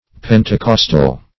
Pentecostal \Pen`te*cos"tal\, a.